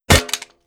Weapon_Drop 04.wav